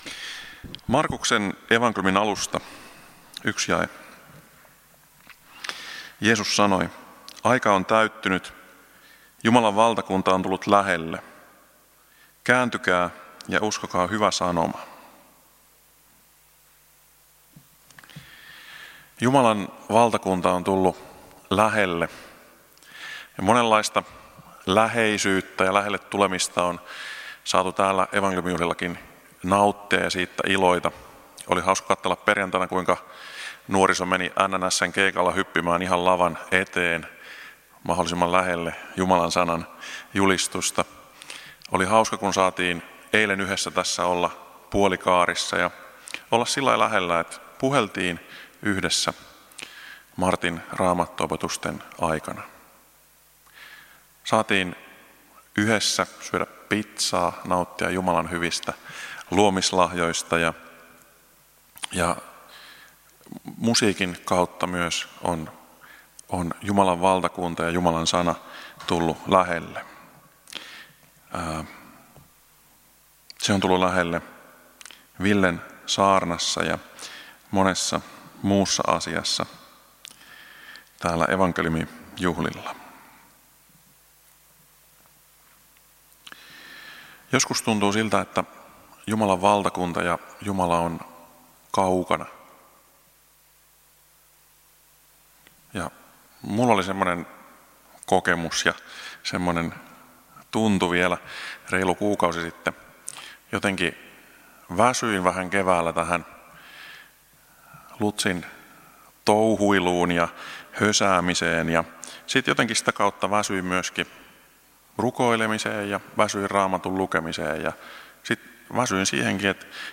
Loppuhartaus
Kokoelmat: Tampereen evankeliumijuhlat 2019